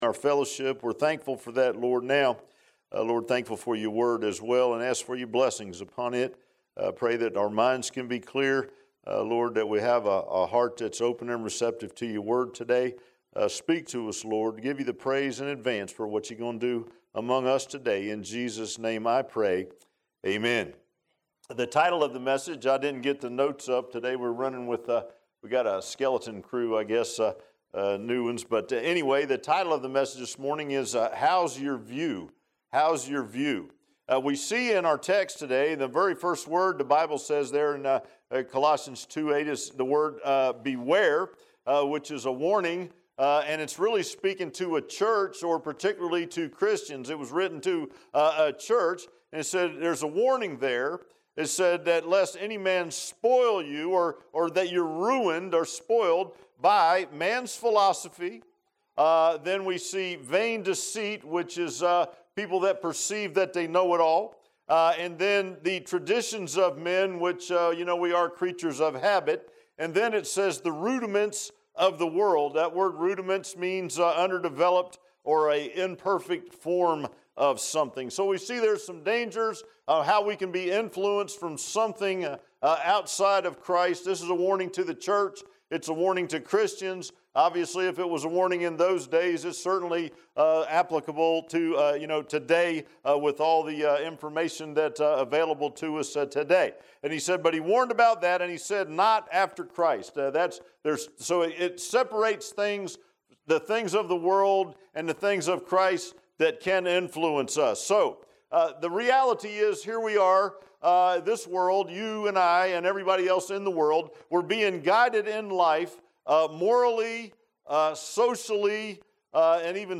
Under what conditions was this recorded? Passage: Colossians 2:8 Service Type: Sunday AM Bible Text